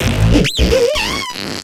Cri de Moufflair dans Pokémon X et Y.